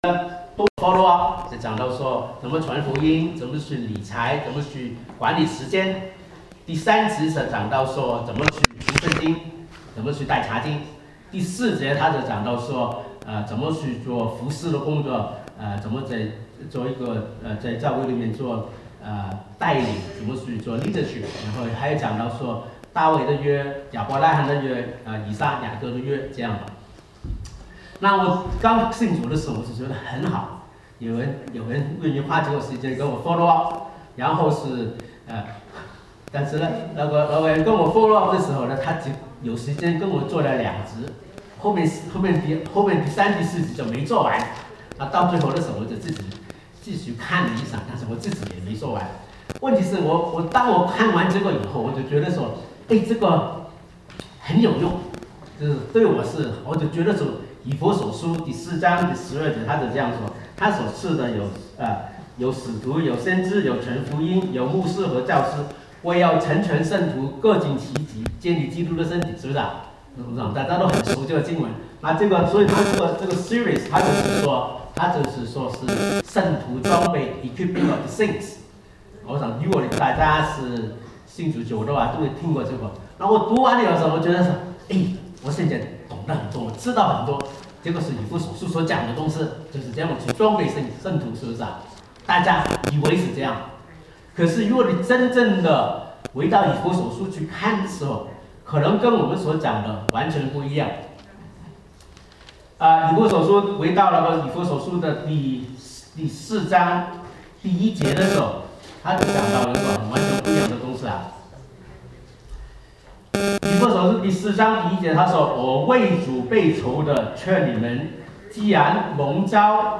1-16 Service Type: Sunday Service Summary 我們是正在建造 ?